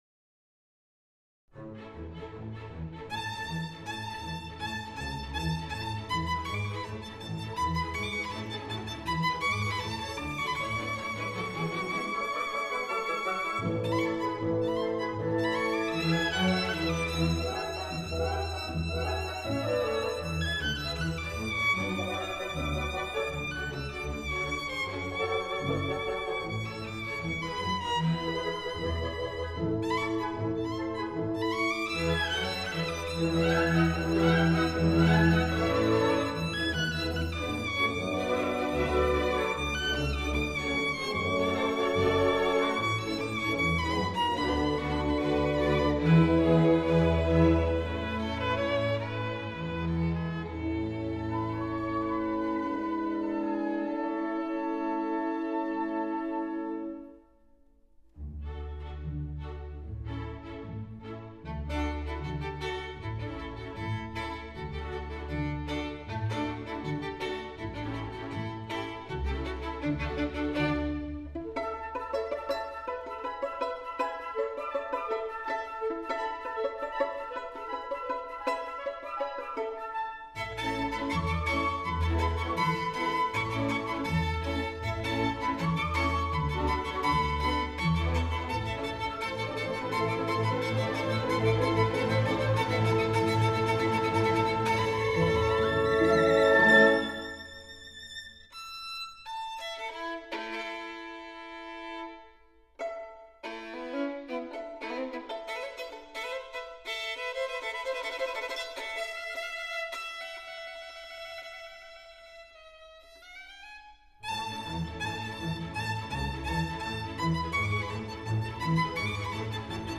小提琴協奏曲